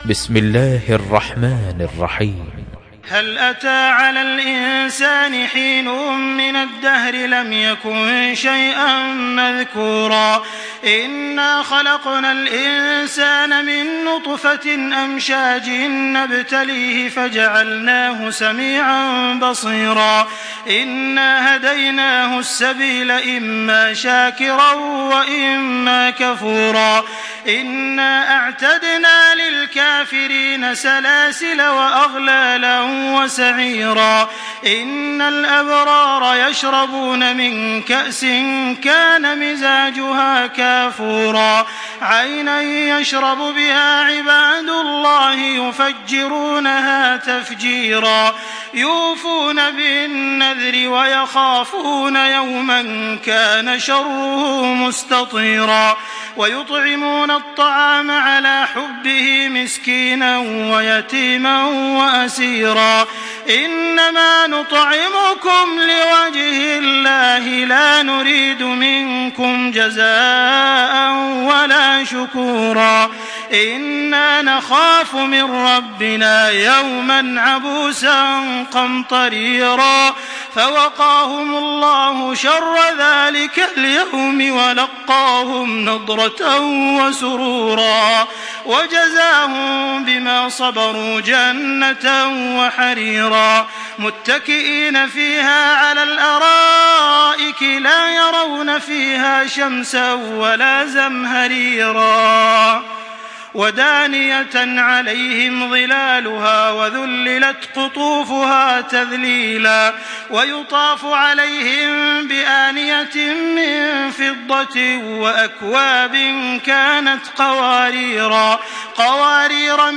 Surah আল-ইনসান MP3 by Makkah Taraweeh 1425 in Hafs An Asim narration.
Murattal